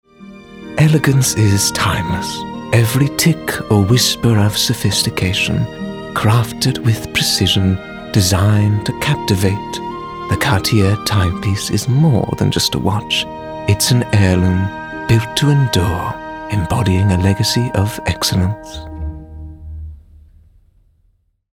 authoritative, character, confident, Deep, Quirky, smooth
British Cartier